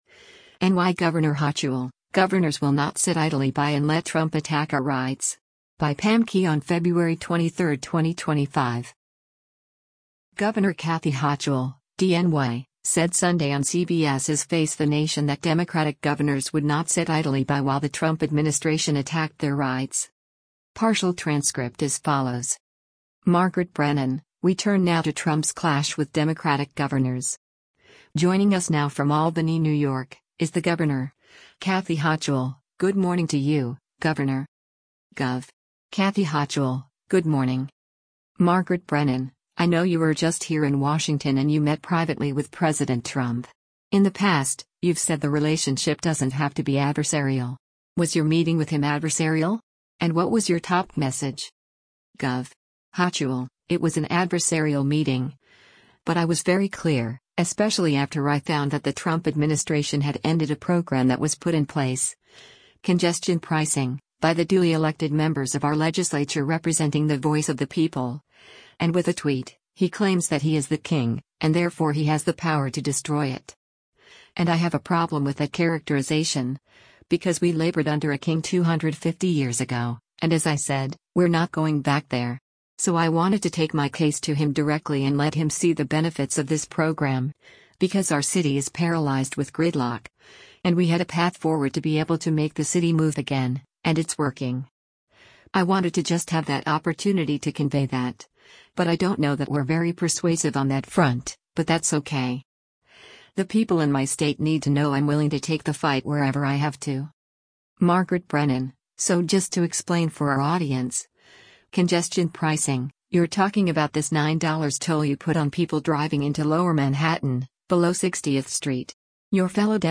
Governor Kathy Hochul (D-NY) said Sunday on CBS’s “Face the Nation” that Democratic governors would not sit idly by while the Trump administration attacked their rights.